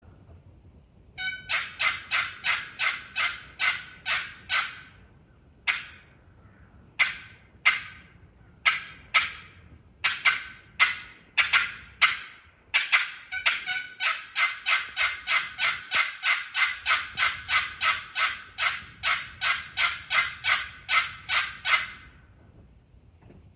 Bloodwood over Curly Maple - High Class Calls